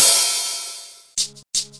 Sound Effect
Rap beat
cymbal